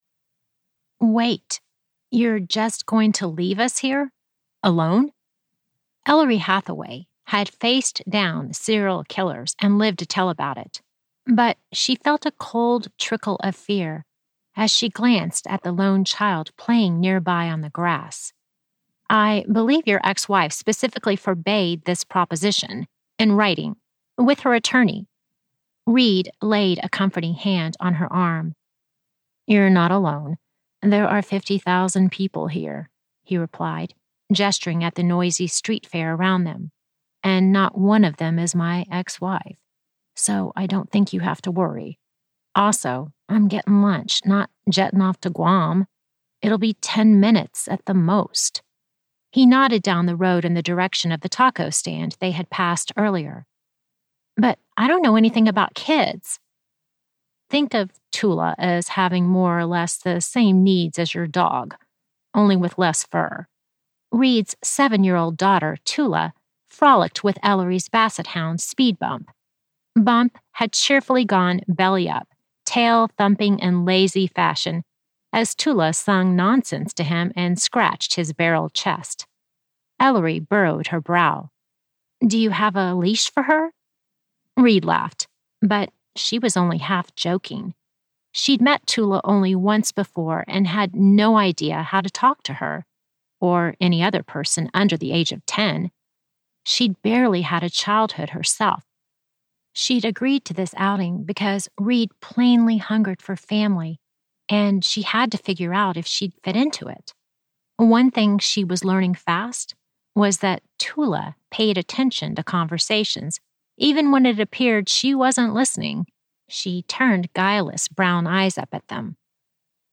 Every Waking Hour - A Mystery - Vibrance Press Audiobooks - Vibrance Press Audiobooks